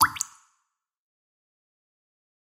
Шум падающей капли воды